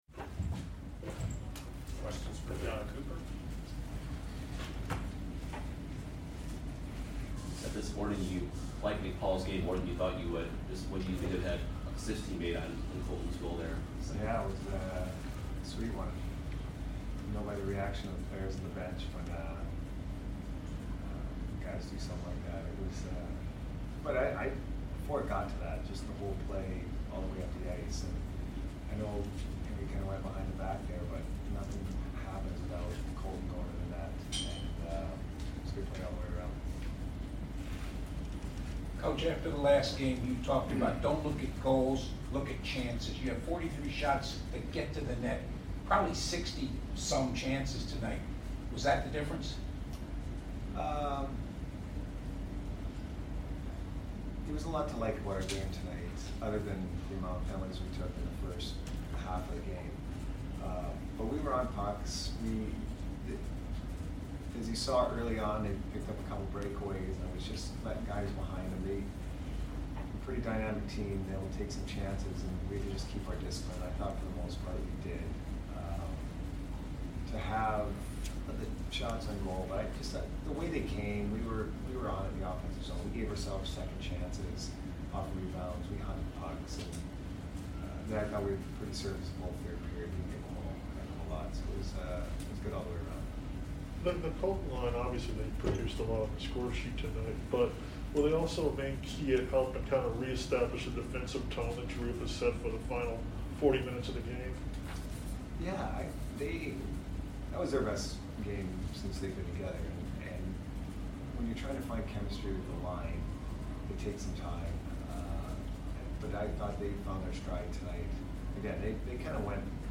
Head Coach Jon Cooper Post Game Vs CHI 4 - 1-2022